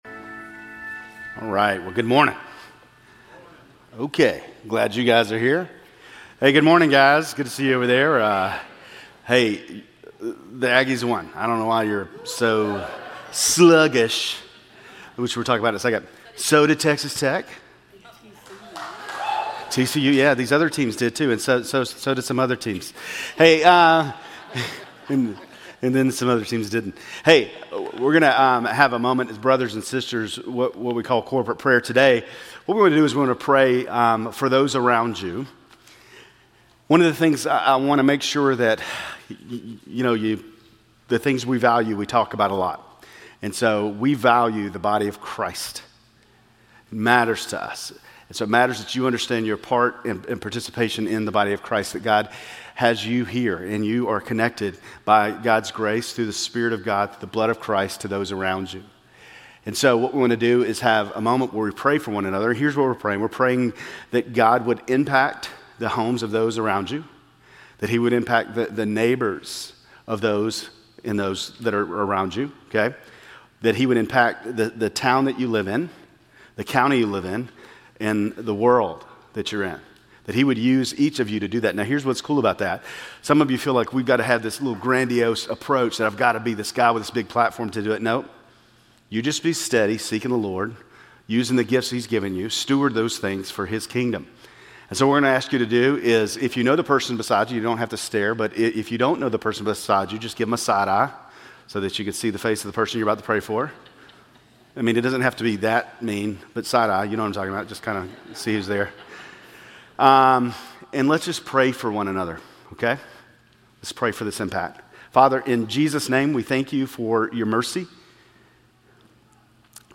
Grace Community Church Lindale Campus Sermons 10_26 Lindale Campus Oct 27 2025 | 00:36:41 1x 00:00 / 00:36:41 Subscribe Share RSS Feed Share Link Embed